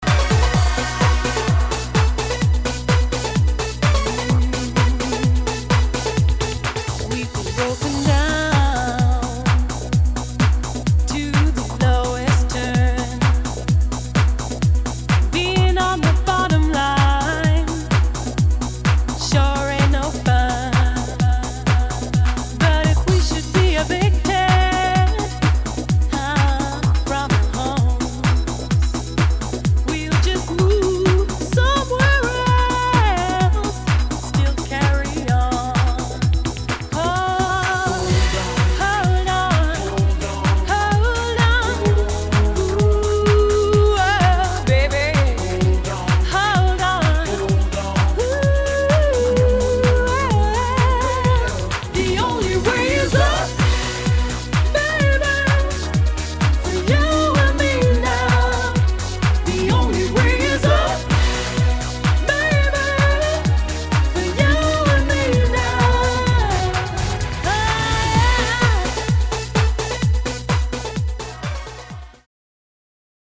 [ 80'S NEW WAVE DISCO / HOUSE / BREAKBEAT ]